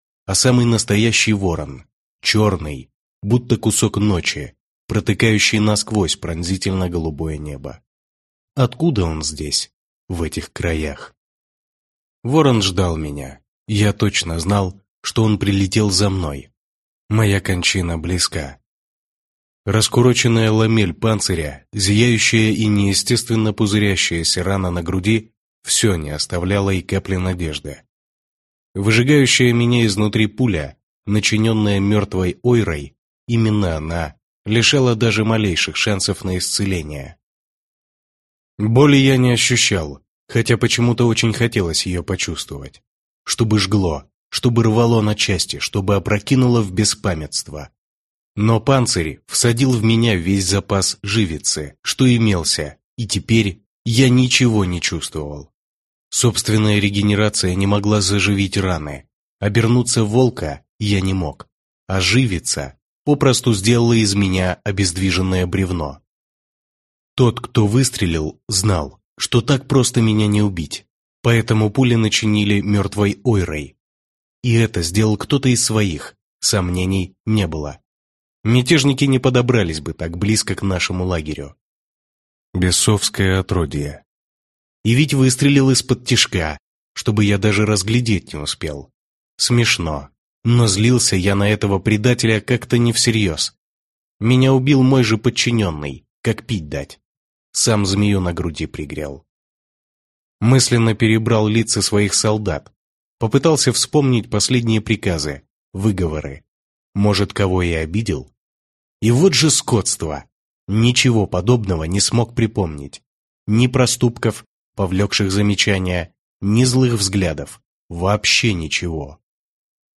Аудиокнига Воронов дар. Книга 1. Меняя Судьбу | Библиотека аудиокниг
Прослушать и бесплатно скачать фрагмент аудиокниги